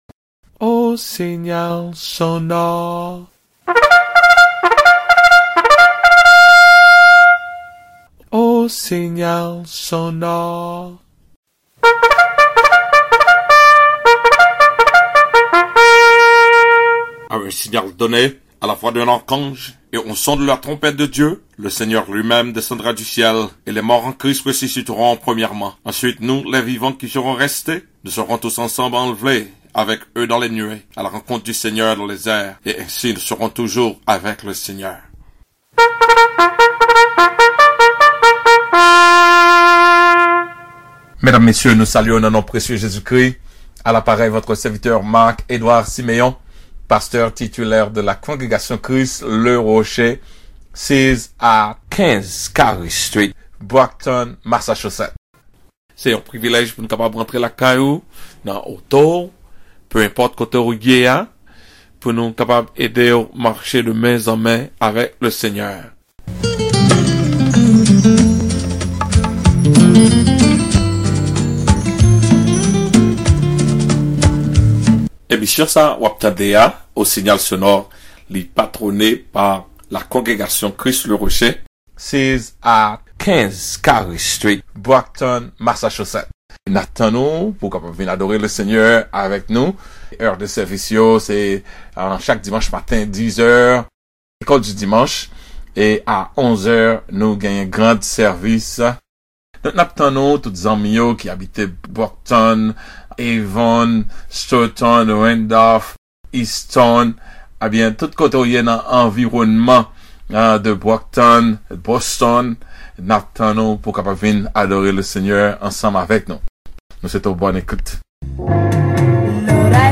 CHRIST THE ROCK CONGREGATION SUNDAY AUGUST 25TH 2019 Sermon